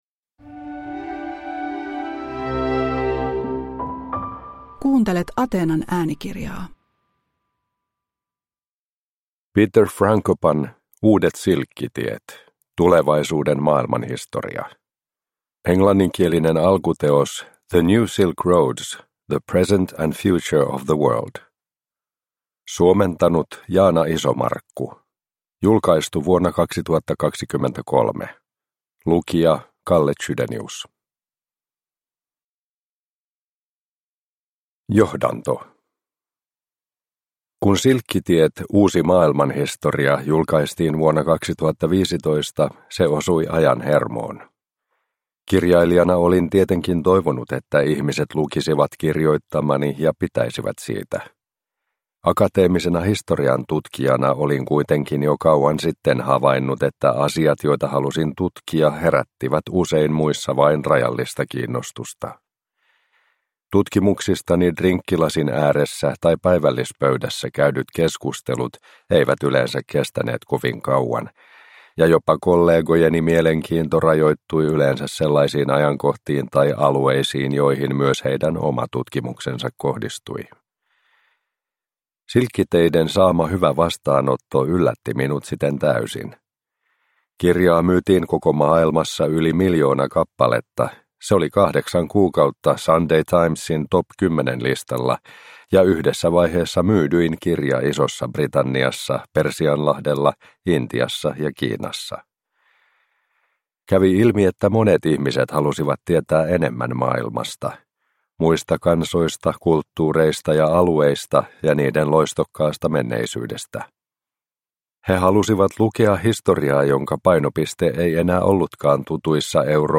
Uudet silkkitiet – Ljudbok